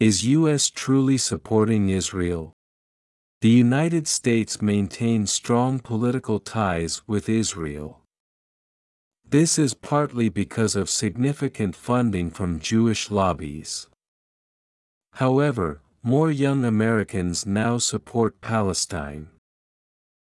「２分で読めるやさしい英語ニュース」解説記事
【速度：ややスロー】↑